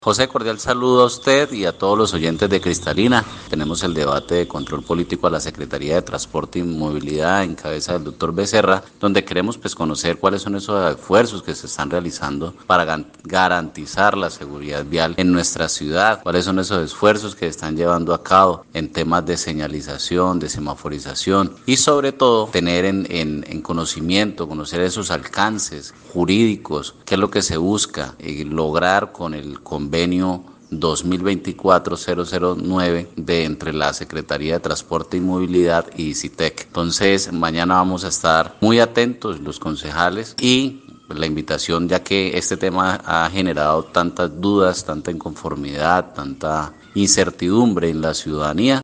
Leonardo Ramírez, concejal por Cambio Radical, explicó que las dudas en torno a temas financieros y de legalidad, son las que presentan varios ediles, por ello estarán atentos a lo que se diga desde la secretaría para avalar o no esta iniciativa, que, si bien ya fue firmada, no tuvo en cuenta al concejo.
CONCEJAL_LEONARDO_RAMIREZ_TRANSITO_-_copia.mp3